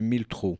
prononciation
1000trous-prononciation.mp3